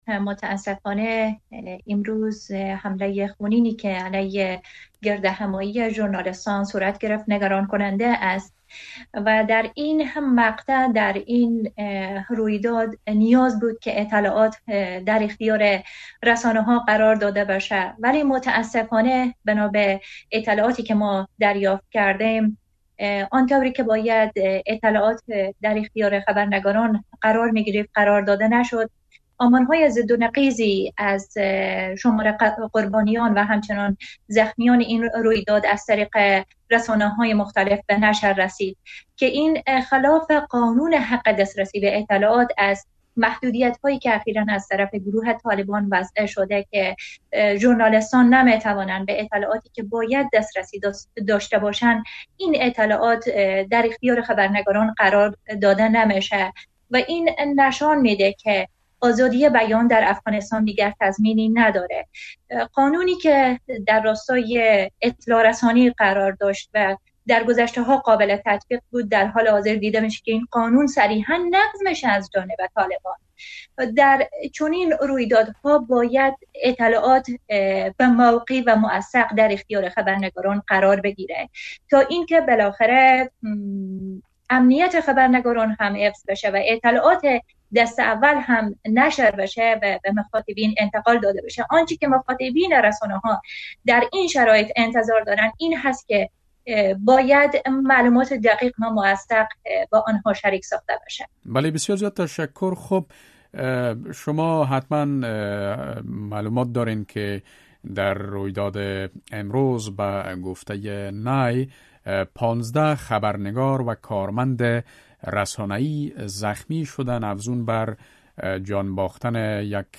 مصاحبه - صدا